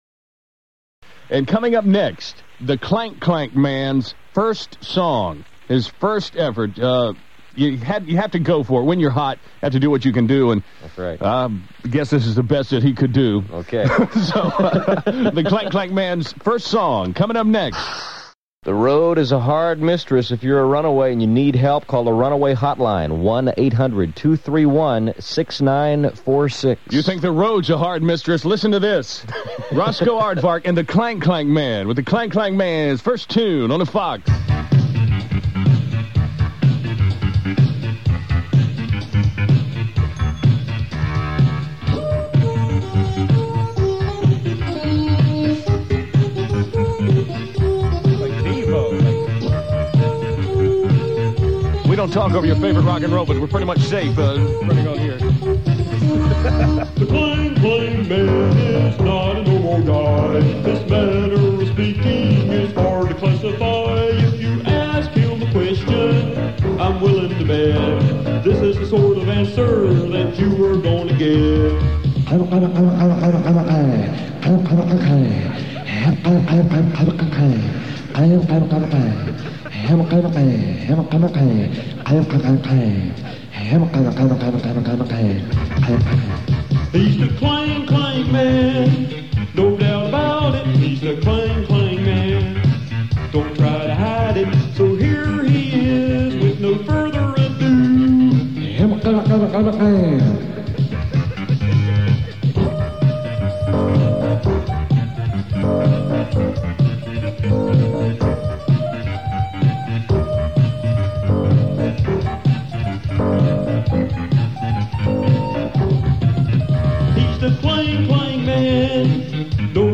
On-the-air version  (file size 2,566,671 bytes)